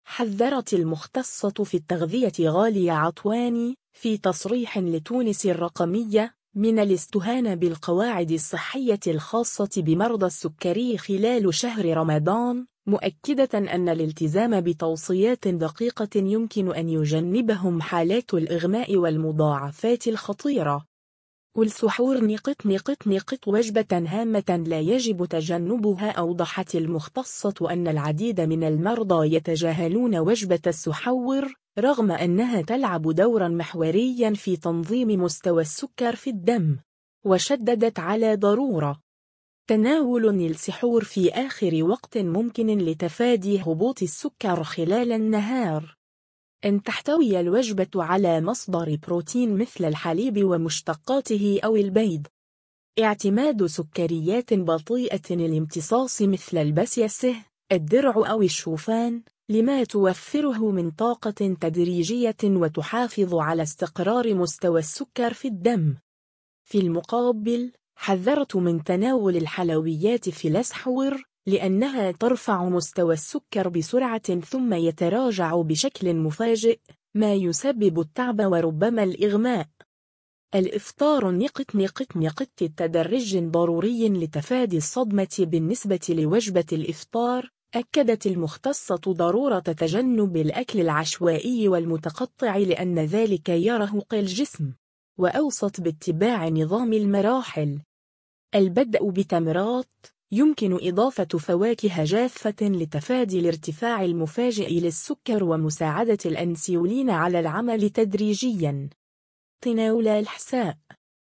في تصريح لتونس الرقمية